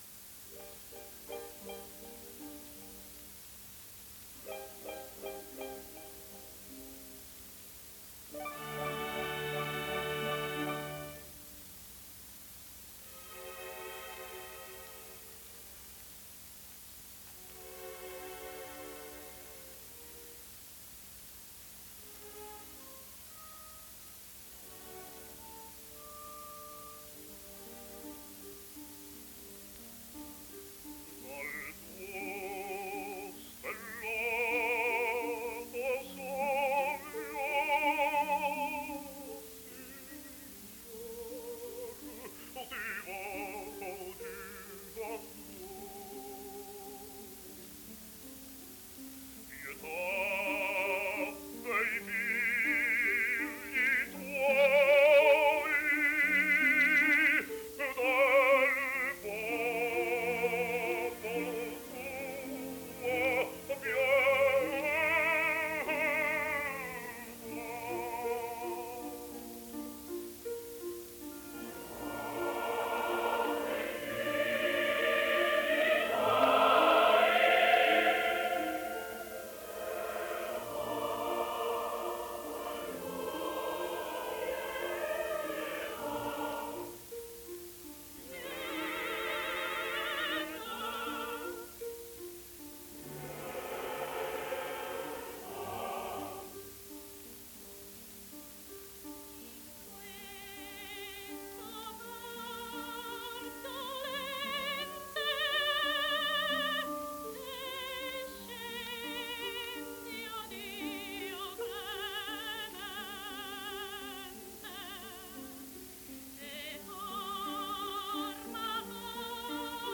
Basso NAZZARENO DE ANGELIS
De Angelis incise moltissimi dischi, sia acustici con la Fonotipia 1905 -1909, sia incisioni elettriche Columbia 1929-1937, di cui sotto ascolterete: